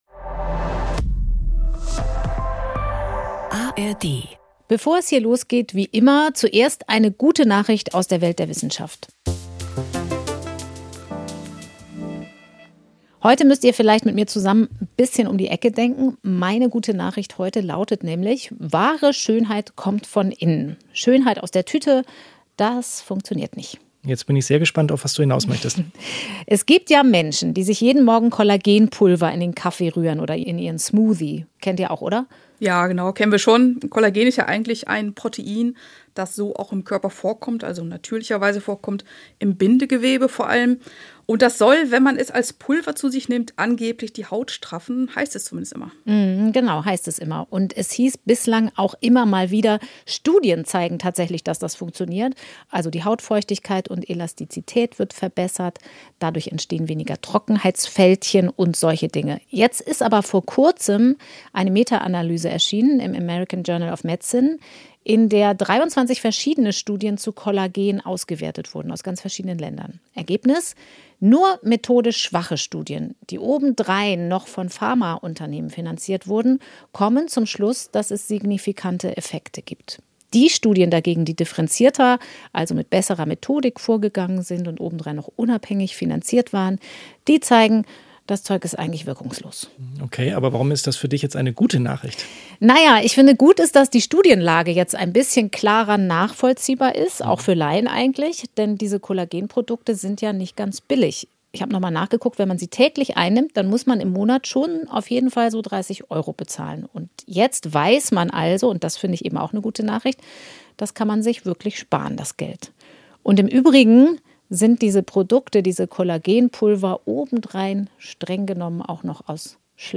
Sie lassen eine betroffene Patientin zu Wort kommen und erklären, warum es keine Schönheits-Operation ohne Restrisiko gibt.